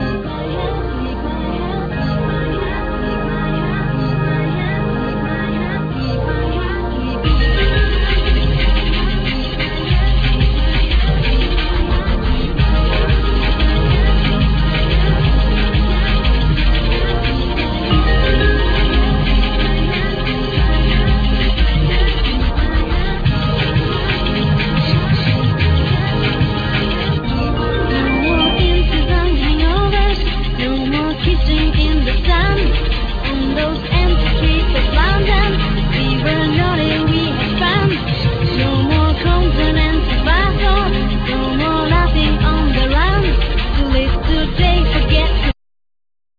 Synthsizer,Drum programming,Guitar
Sampling,Sax,Trumpet